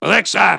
synthetic-wakewords
ovos-tts-plugin-deepponies_Soldier_en.wav